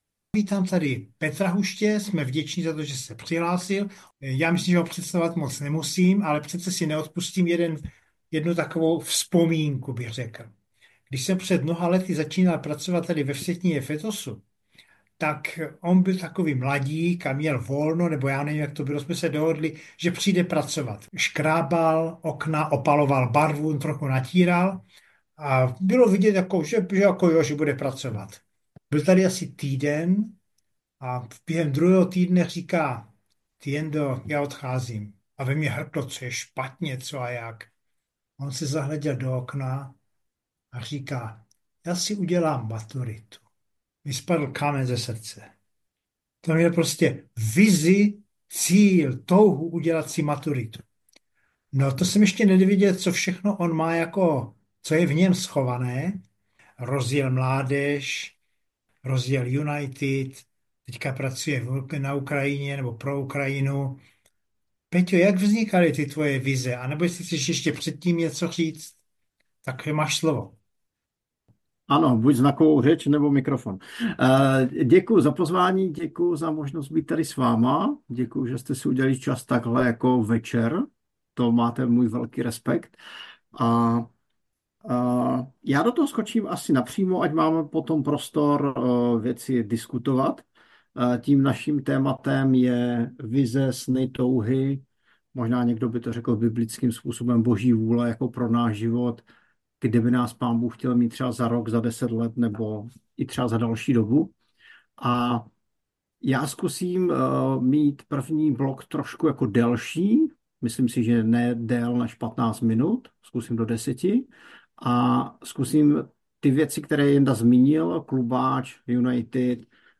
Webinář